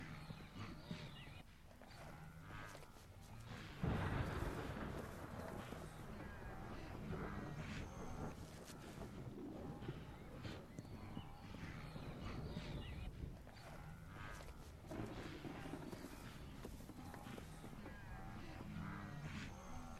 Ambiance Orage en brousse (Broadcast) – Le Studio JeeeP Prod
Bruits d’ambiance au moment d’un orage en brousse.
Ambiance-Brousse-africaine-sous-lorage.mp3